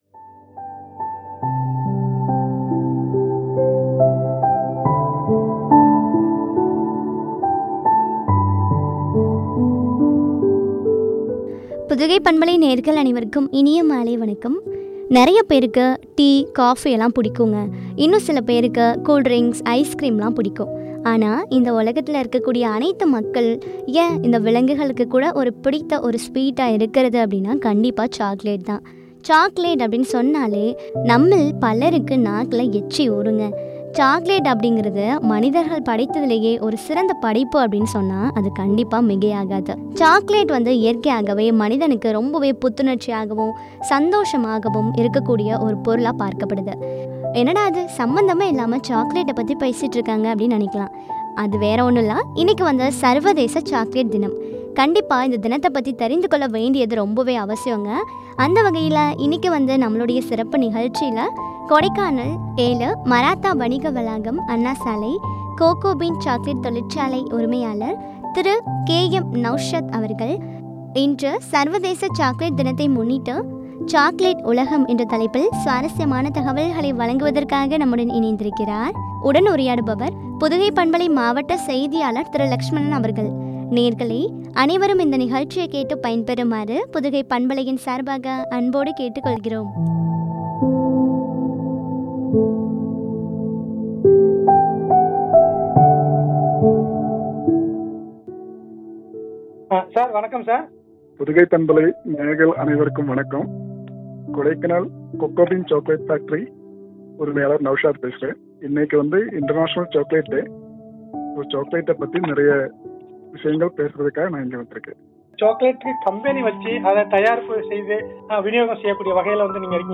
வழங்கிய உரையாடல்.